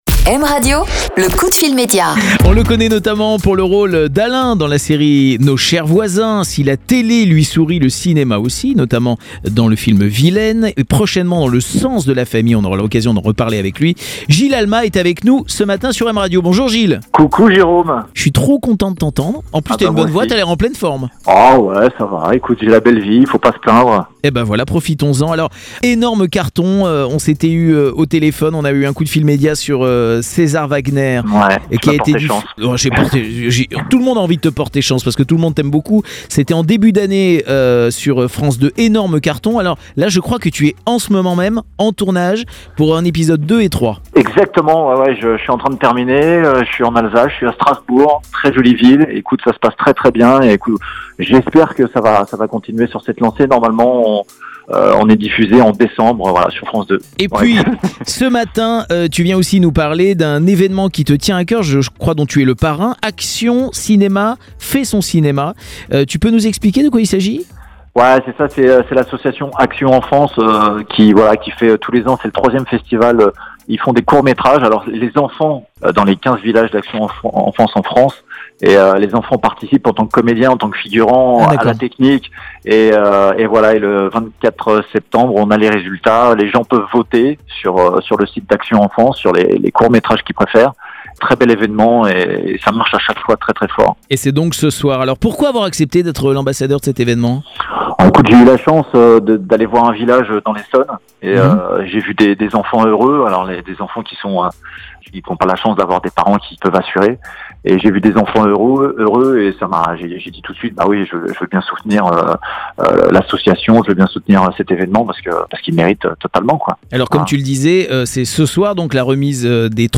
Ce matin Jerôme Anthony recevait Gil Alma en coup de fil Média sur M Radio